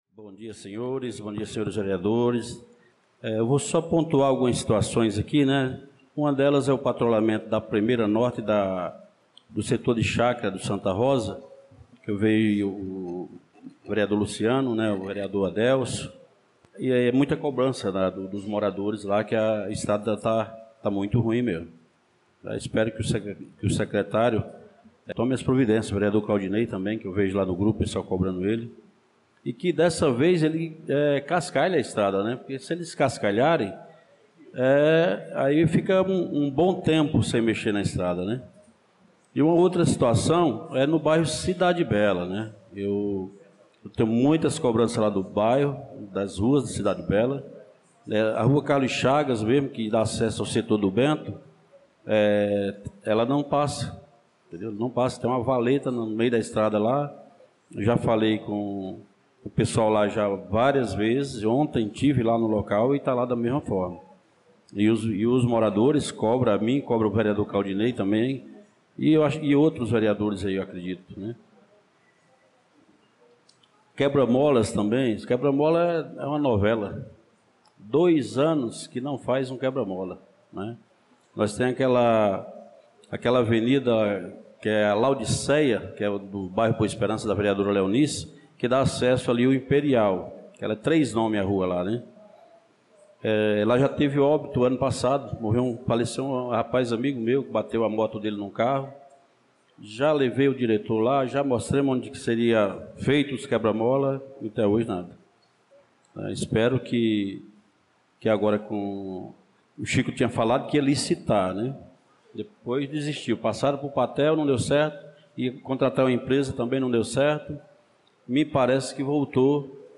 Pronunciamento do vereador Francisco Ailton na Sessão Ordinária do dia 18/02/2025